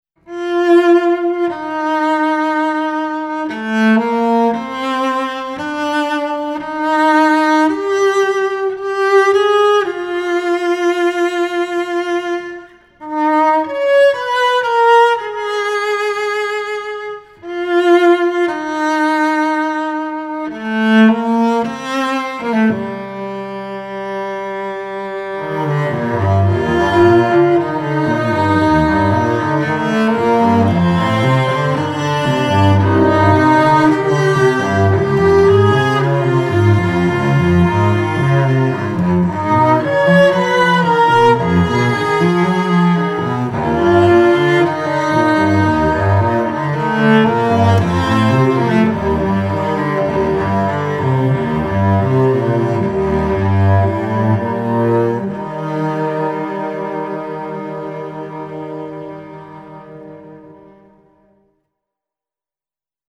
Cello ensemble